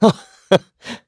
Clause_ice-Vox-Laugh_kr_b.wav